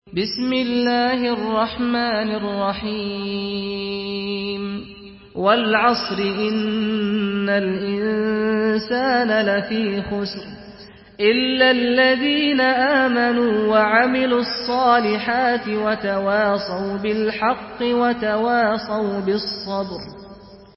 Surah Asr MP3 by Saad Al-Ghamdi in Hafs An Asim narration.
Murattal Hafs An Asim